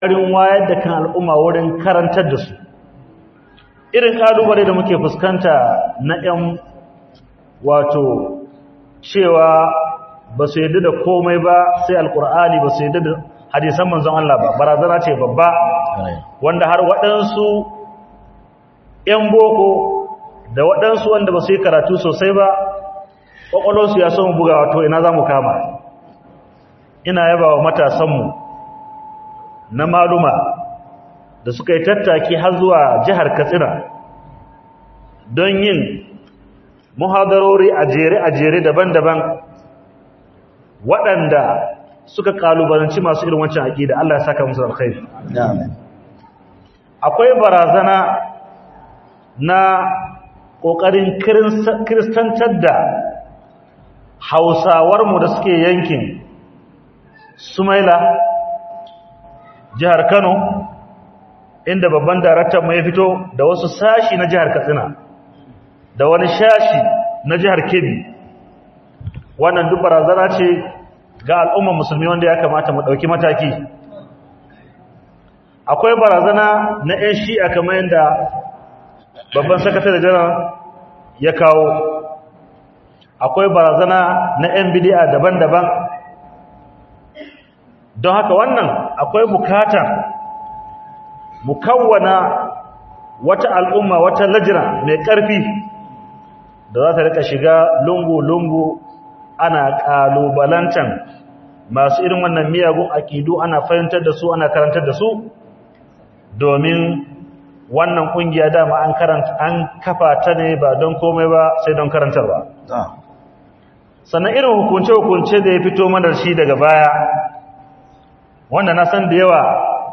Hatsin Bara Abuja - MUHADARA by Sheikh (Dr.) Abdullahi Bala Lau